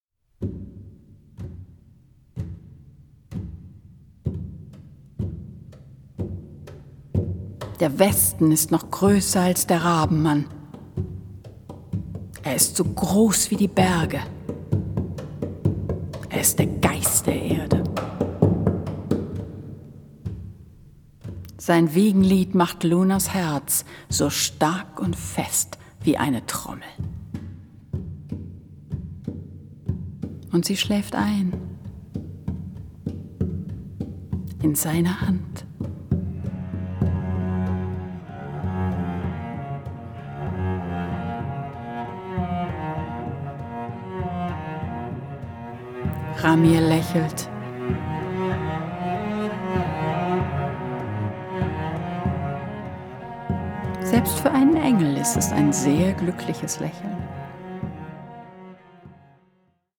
mitreißende Gesänge
begleitet von den üppigen Cellos